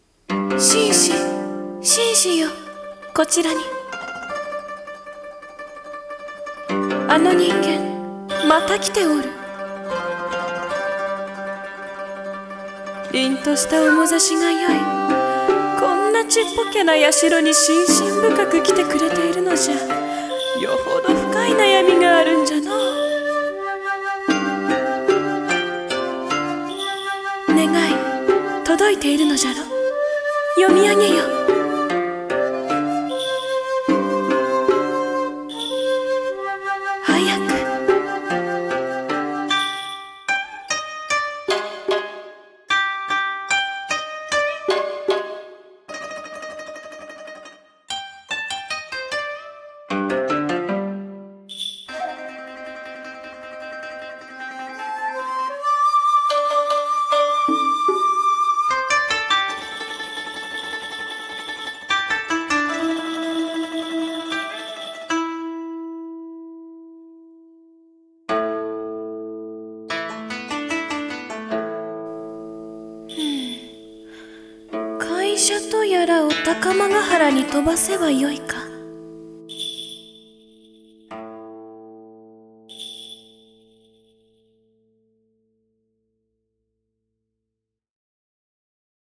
【和風台本】神様はみている【声劇】